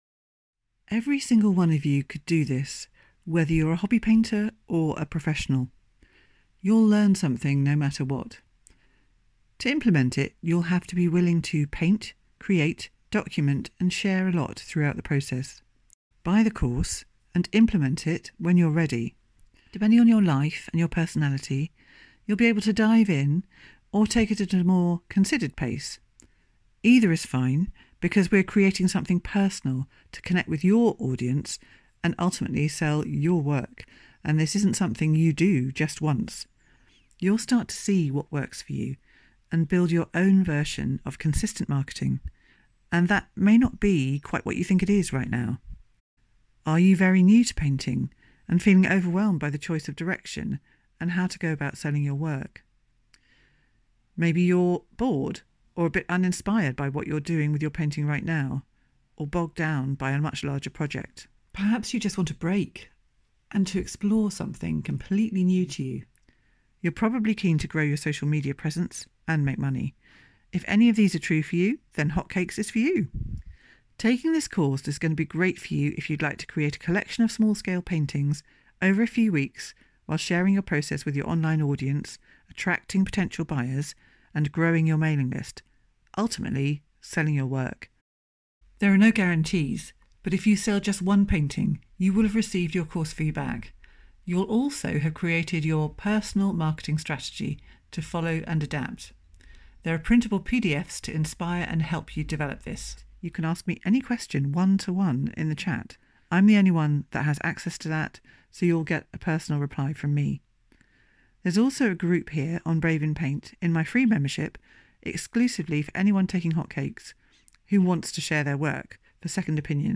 Listen to me read a version of what I've written below here